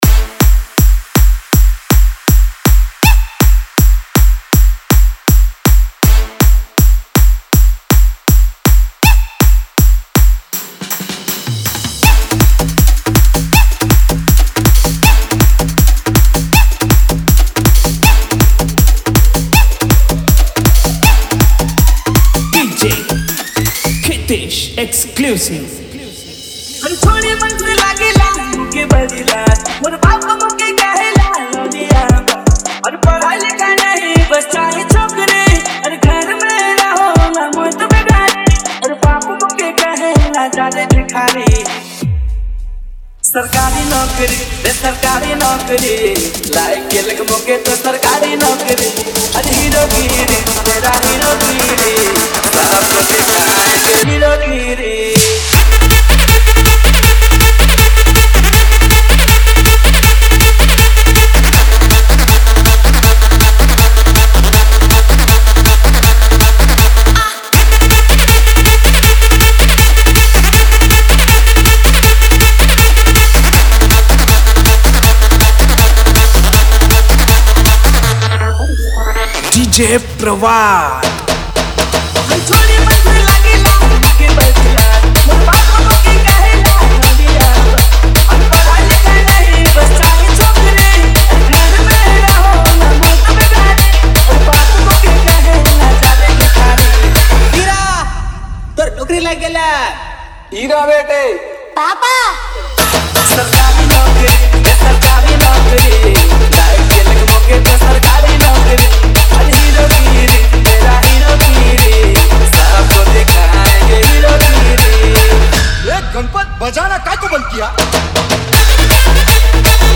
• Category: ODIA SINGLE REMIX